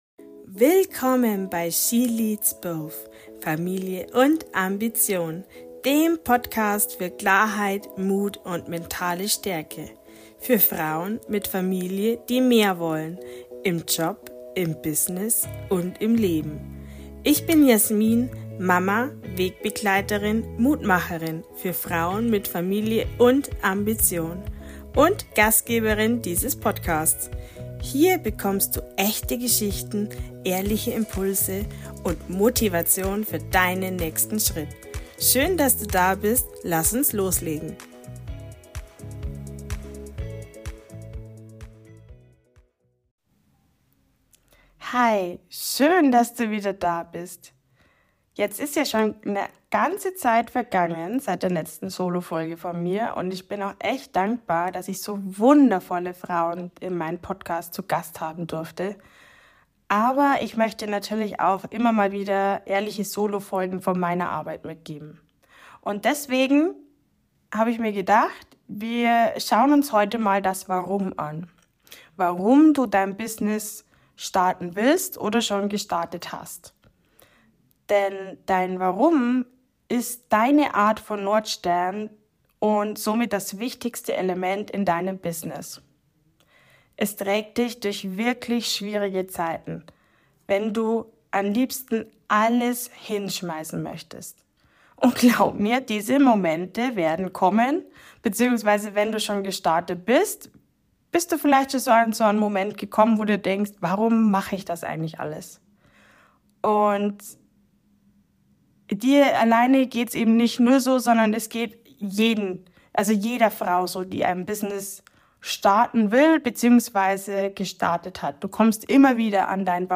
In dieser Solo-Folge nehme ich dich mit zu deinem innersten Antrieb: deinem Warum.